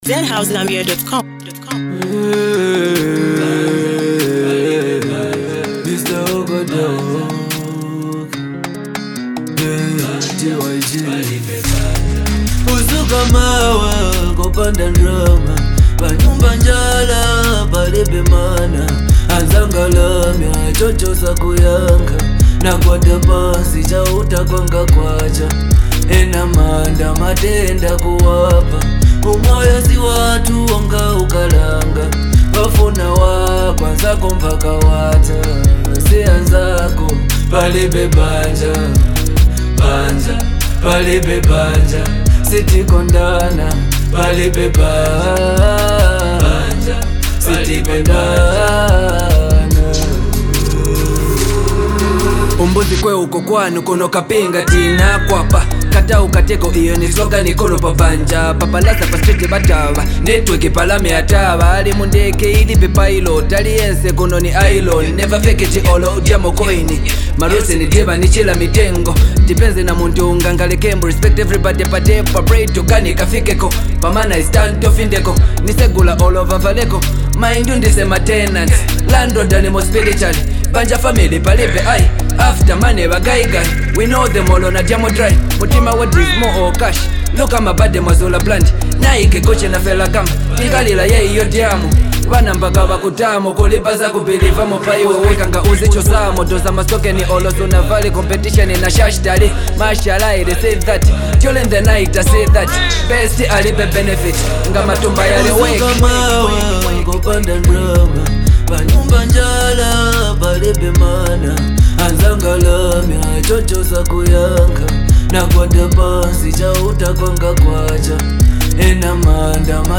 raw and real hip hop anthem